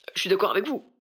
VO_ALL_Interjection_18.ogg